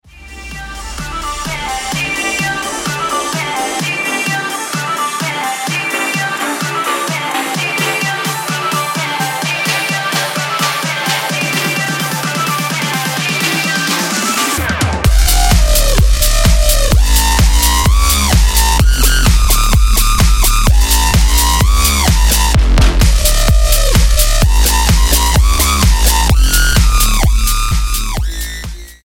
STYLE: Dance/Electronic
Yep, these guys can deliver a great clubland groove.